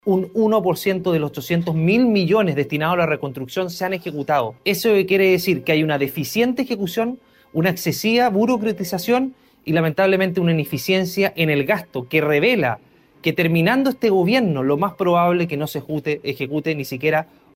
Quien también realizó fuertes críticas a las instituciones gubernamentales fue su par, Andrés Longton, quien afirmó que existe una gran ineficiencia en el manejo de los gastos de los dineros asignados al proceso de reconstrucción, prolongando su demora y perjudicando aún más a los damnificados del megaincendio.